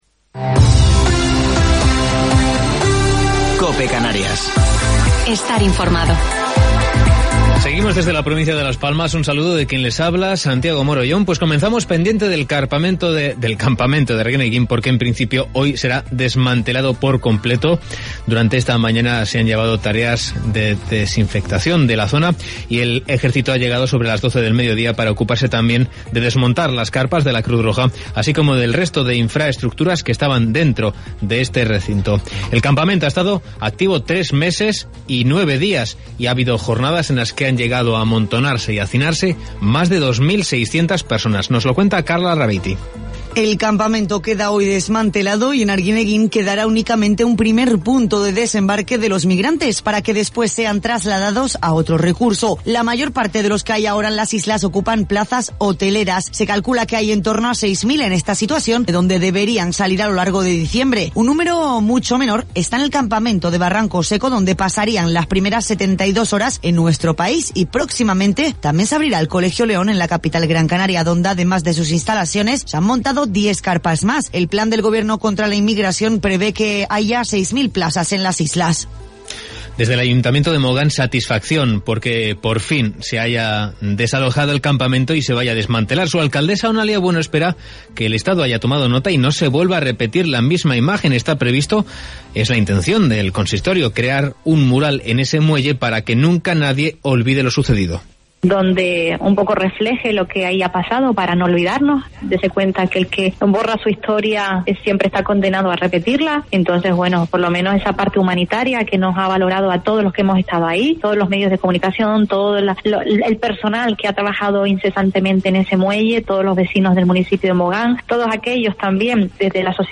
Informativo local 30 de Noviembre del 2020